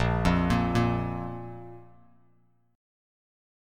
A#5 Chord
Listen to A#5 strummed